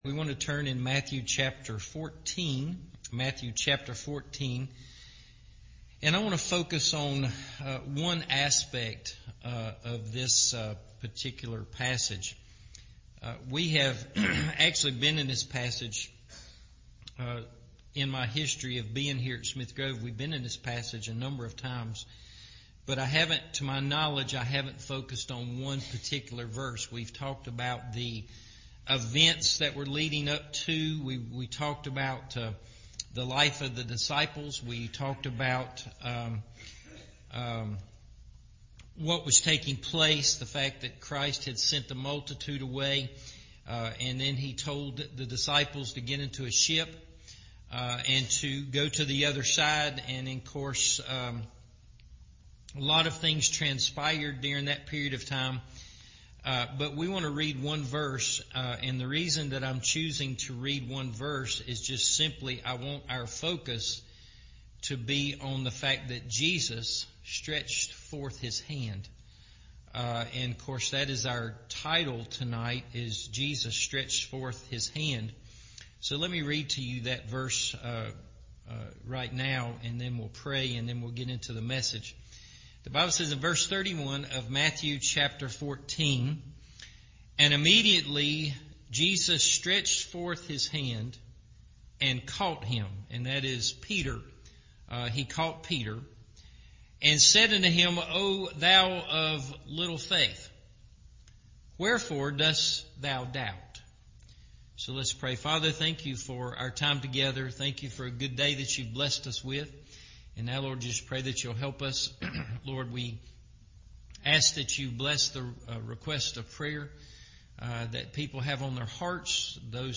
Jesus Stretched Forth His Hand – Evening Service